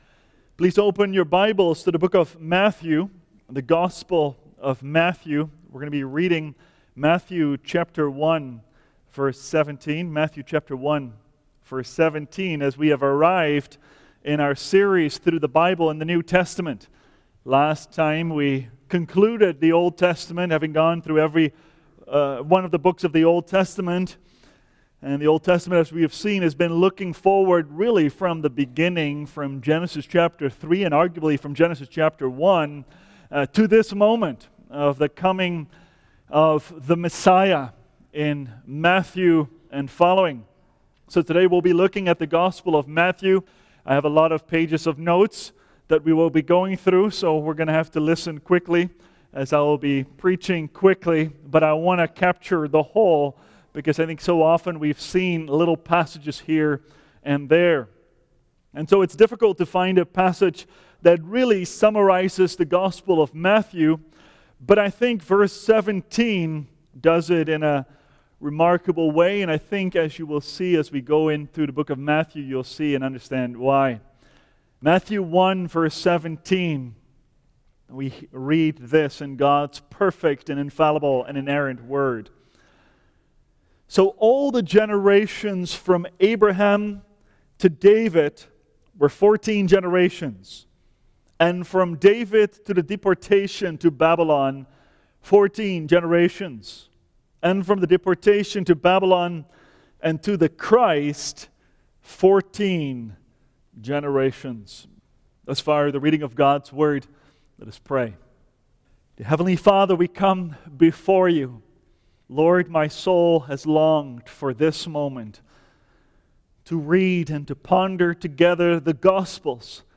– Seventh Reformed Church